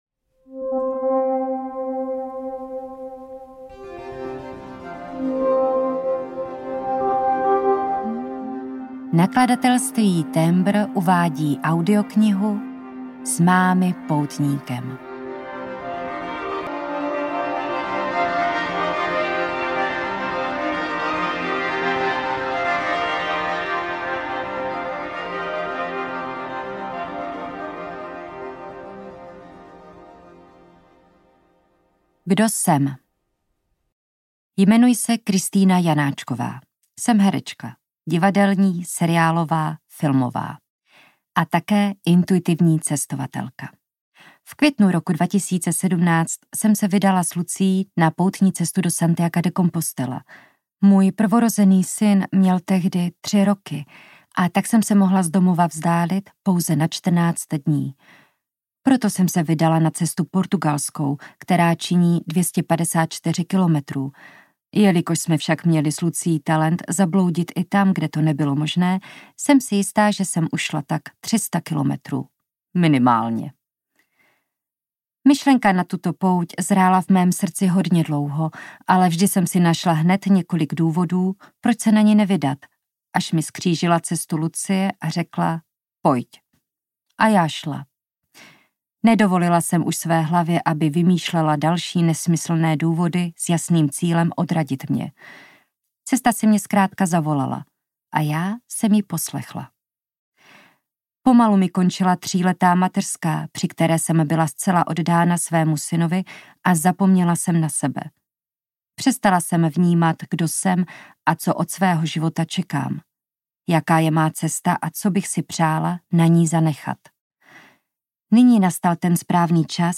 Ukázka z knihy
Natočeno ve studiu S Pro Alfa CZ
z-mamy-poutnikem-audiokniha